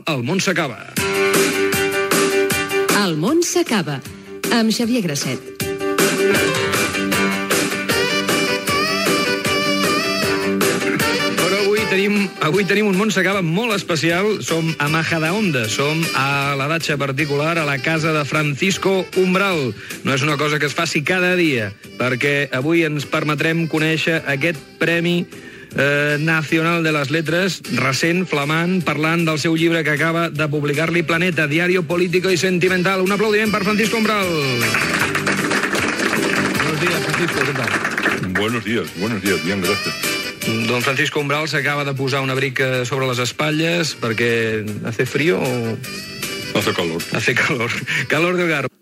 Indicatiu del programa, presentació de l'espai fet a la casa de l'escriptor Francisco Umbral
Entreteniment